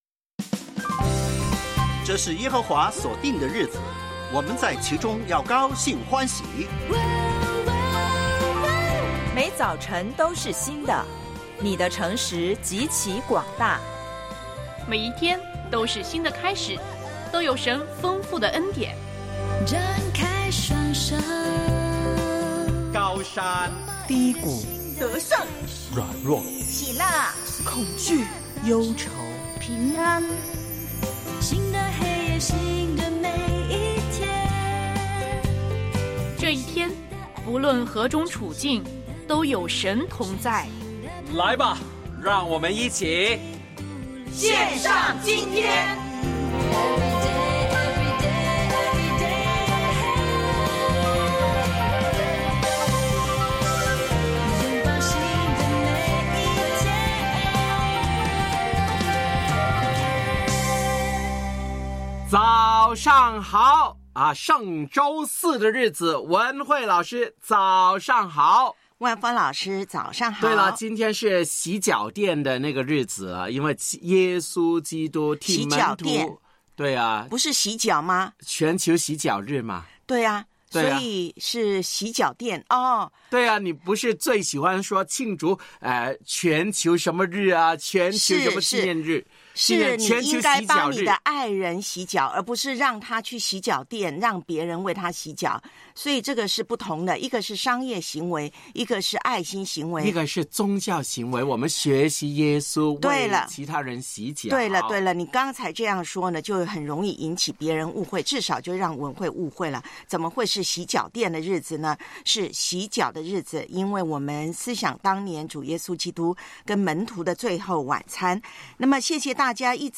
教会年历灵修：约翰福音13:1-17；复活节广播剧（4）耶稣升天；我爱背金句：撒母耳记上7:12